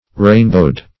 Rainbowed \Rain"bowed`\ (r[=a]n"b[=o]d`)